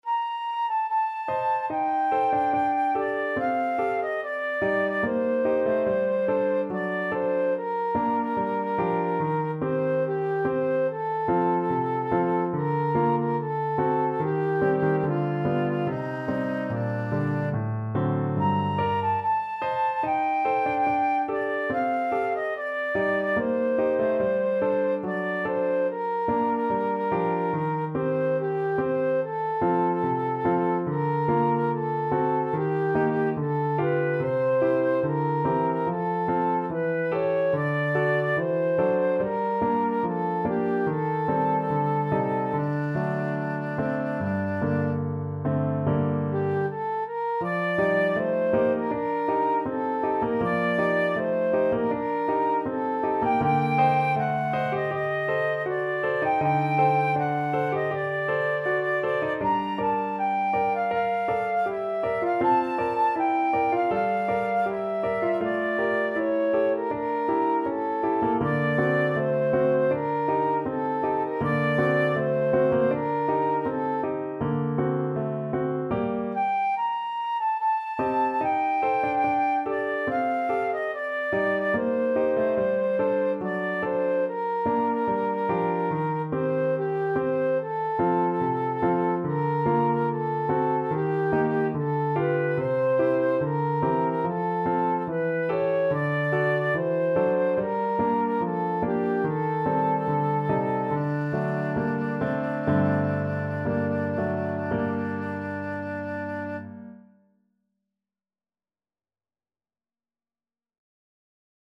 2/4 (View more 2/4 Music)
~ = 72 Andantino (View more music marked Andantino)
D5-Bb6
Classical (View more Classical Flute Music)